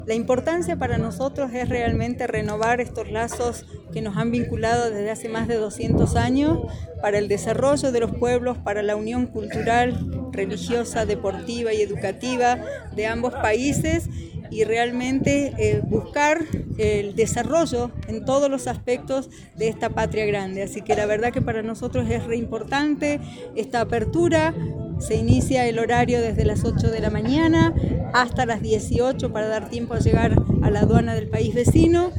En esta línea, las autoridades neuquinas valoraron la coordinación binacional para concretar esta iniciativa, subrayando la importancia de la integración como motor de crecimiento, según manifestó Silvia Canales, intendenta de la localidad de El Cholar, el primer pueblo vecino en Argentina.